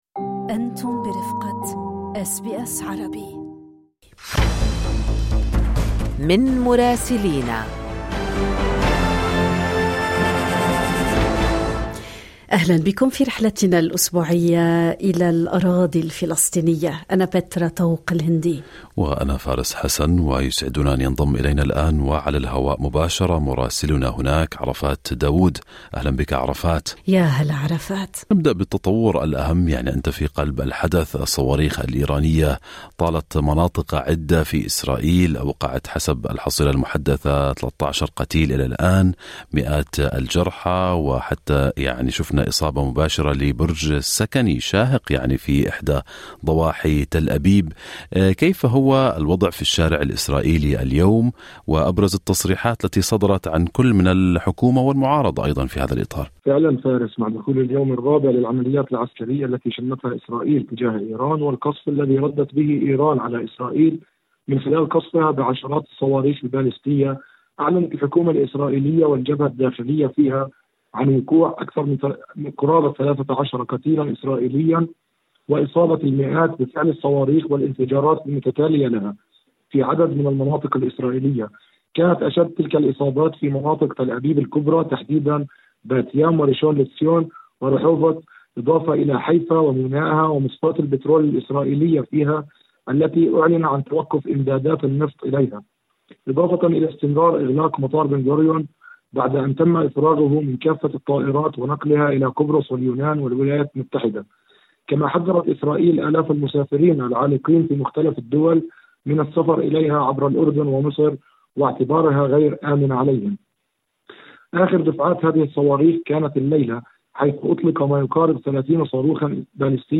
قتلى عرب بصواريخ إيرانية في إسرائيل، استنفار في تل أبيب وأخبار أخرى مع مراسلنا في رام الله